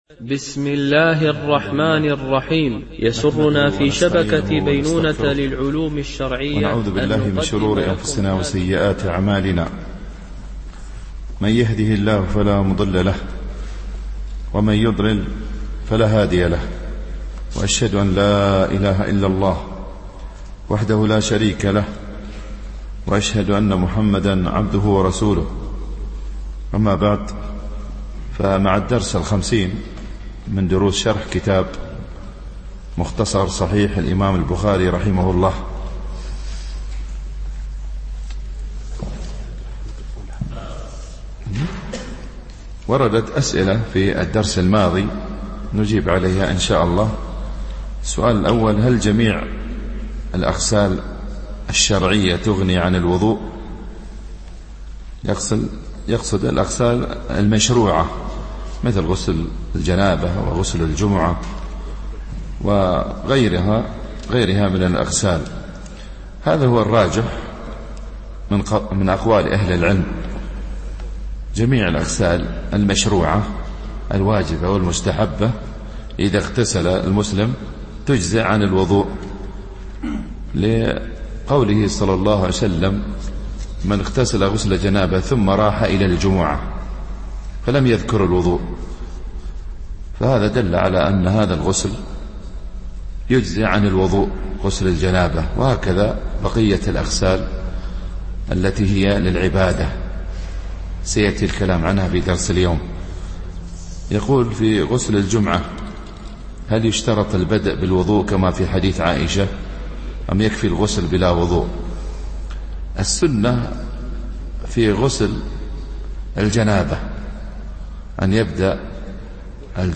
شرح مختصر صحيح البخاري ـ الدرس 50 ( الحديث 195- 202)